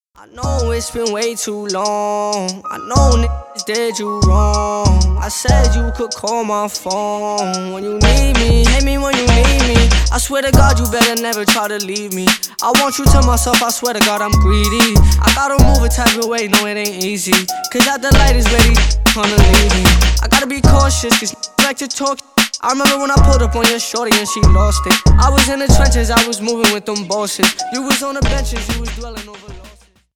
Stereo
Рэп и Хип Хоп